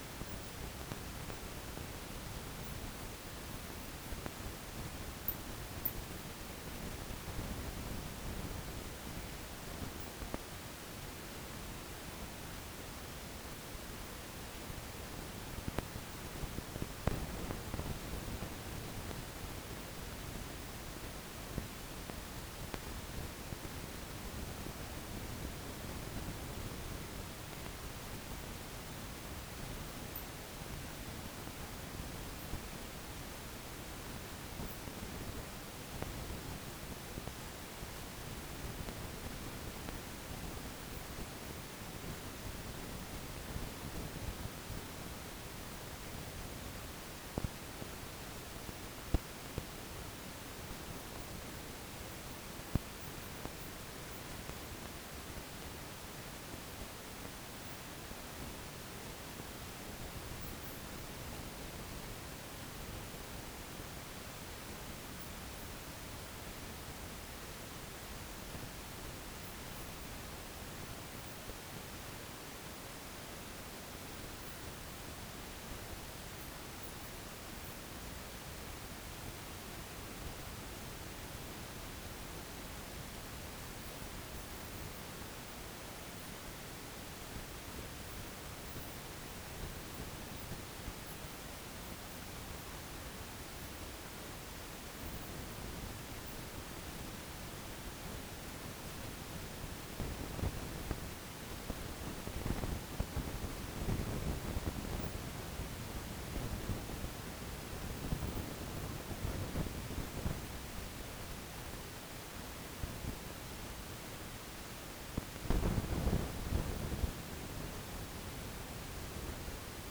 Demonstration soundscapes
biophony
equipment self-noise